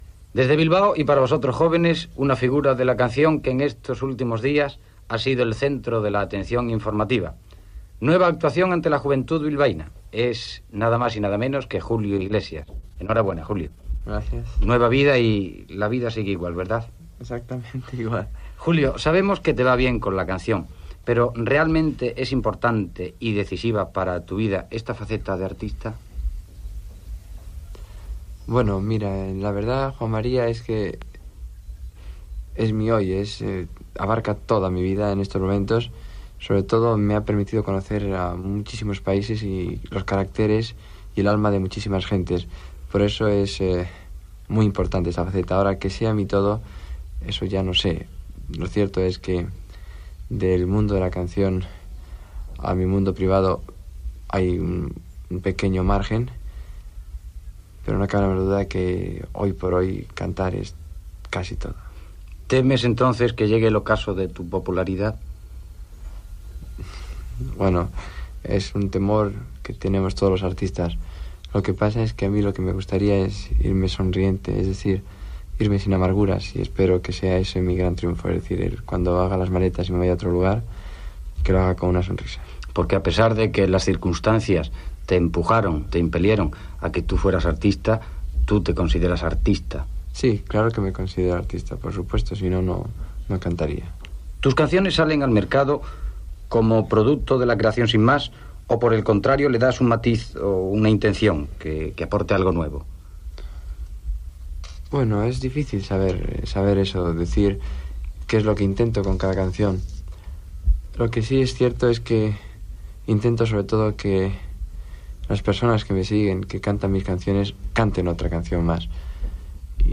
Entrevista al cantant Julio Iglesias després d'actuar a Bilbao.